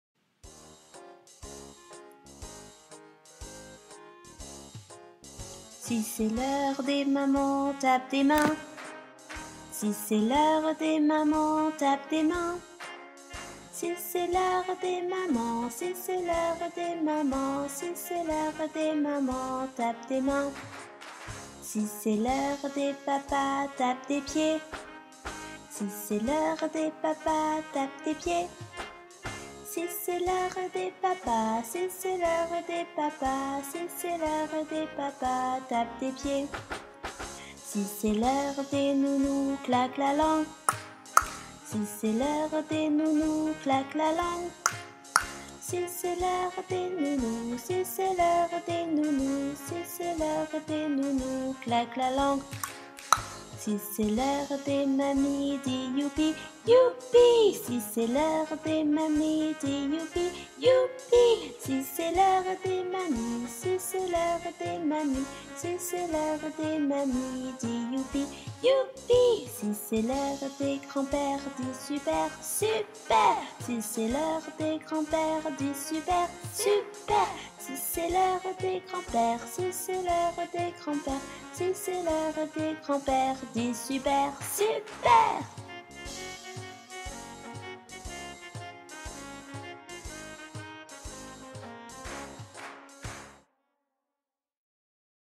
Chansons et comptines PS-GS 2024-2025